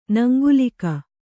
♪ naŋgulika